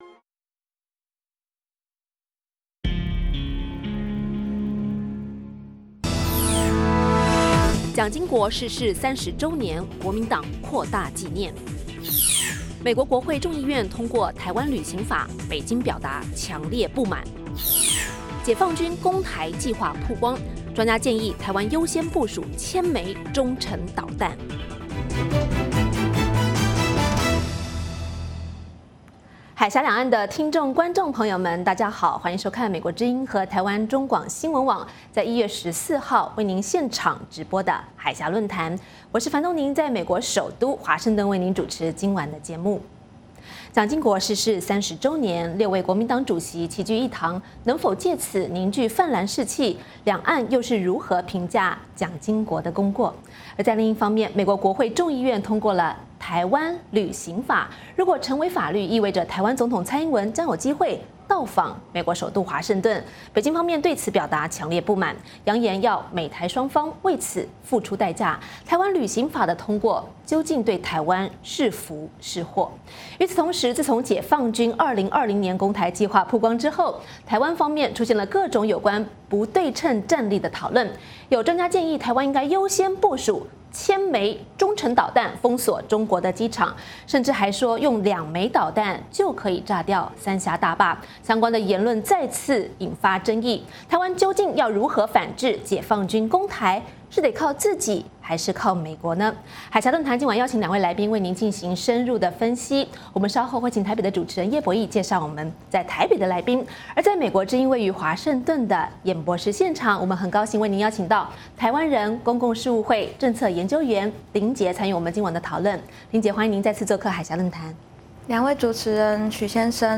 美国之音中文广播于北京时间每周日晚上9-10点播出《海峡论谈》节目(电视、广播同步播出)。《海峡论谈》节目邀请华盛顿和台北专家学者现场讨论政治、经济等各种两岸最新热门话题。